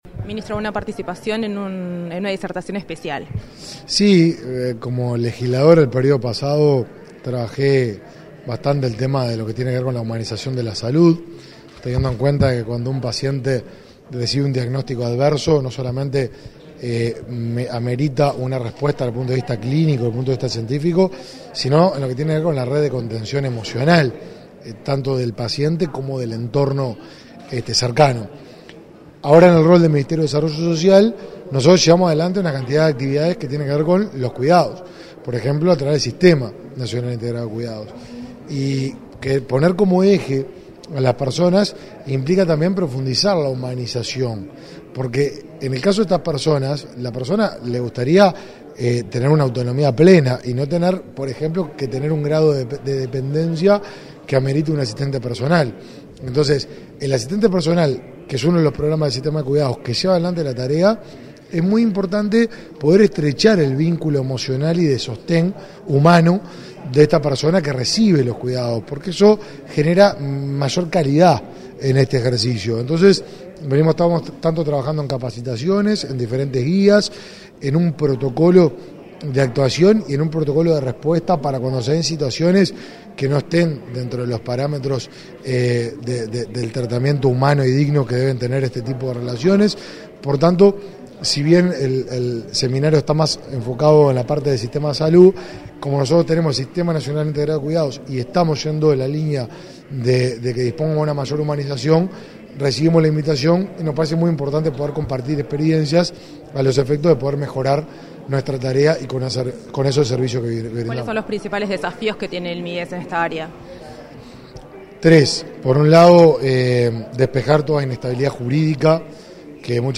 Entrevista al ministro de Desarrollo Social, Martín Lema
Tras el evento, dialogó con Comunicación Presidencial.